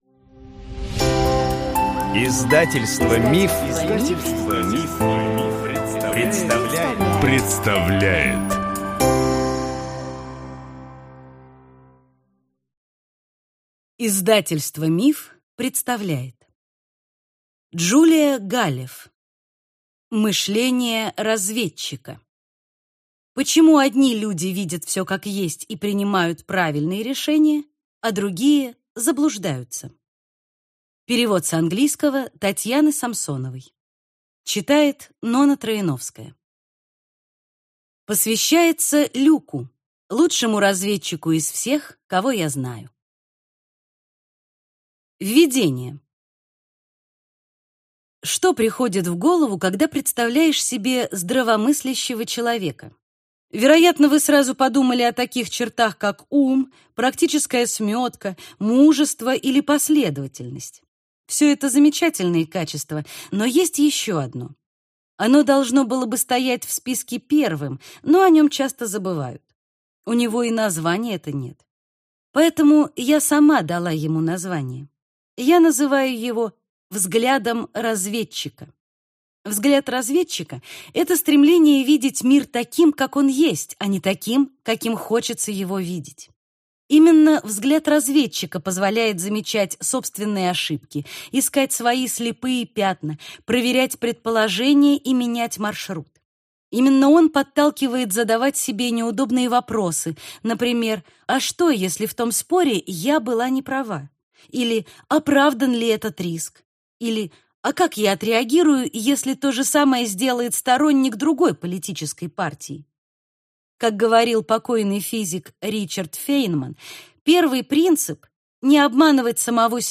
Аудиокнига Мышление разведчика. Почему одни люди видят все как есть и принимают правильные решения, а другие – заблуждаются | Библиотека аудиокниг